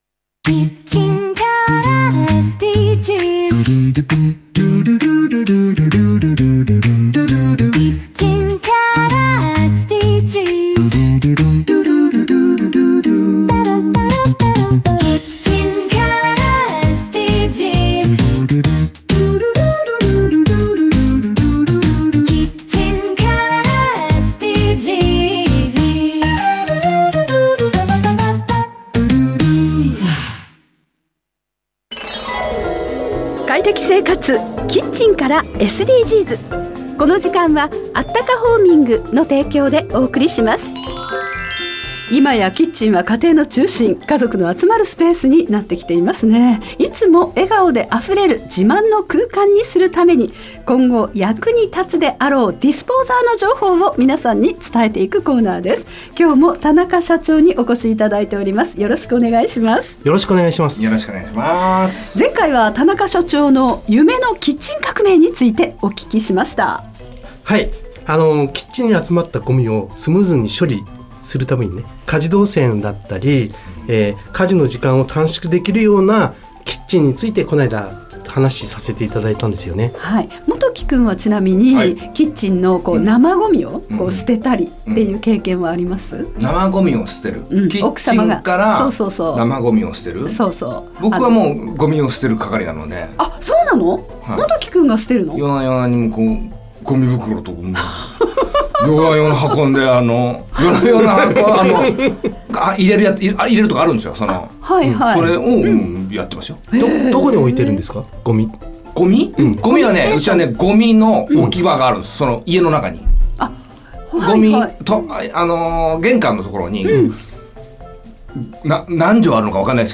【ラジオ】快適生活 キッチンからSDGs 放送中 （ じゃらら（JAGA）10時30分～45分 ）